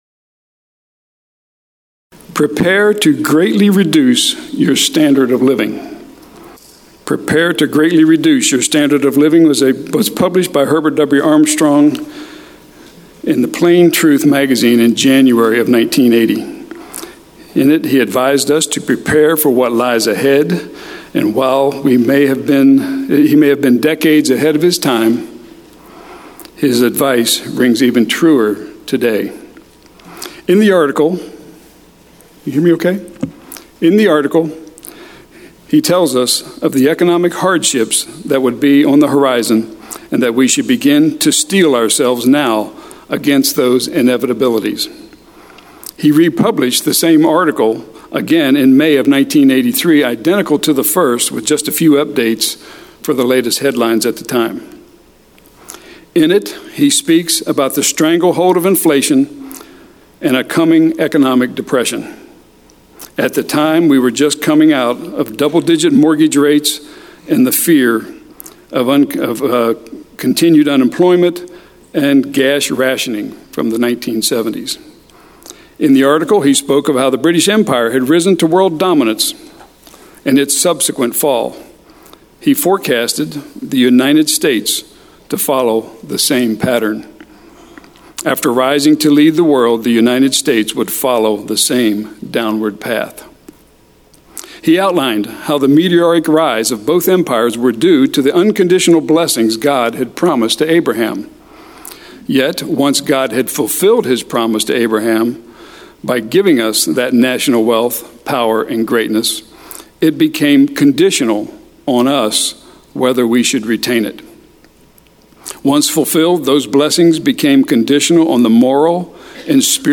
split sermon